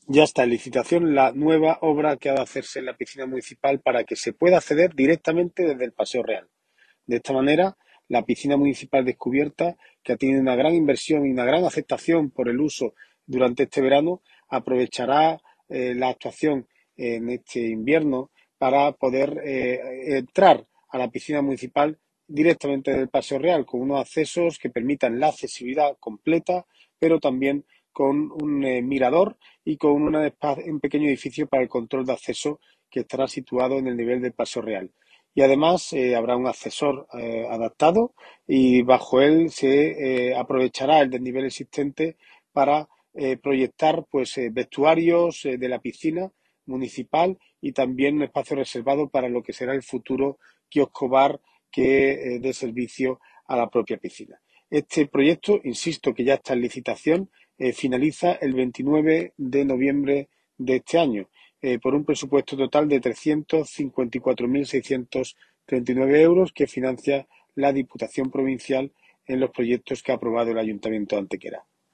El concejal de Obras del Ayuntamiento de Antequera, José Ramón Carmona, informa de la apertura del plazo de presentación de solicitudes para optar a la adjudicación de la construcción del nuevo edificio de acceso, aseos y vestuarios de la Piscina Municipal Descubierta de Antequera, proyecto que va a promover el Ayuntamiento de Antequera con financiación de la Diputación Provincial de Málaga.
Cortes de voz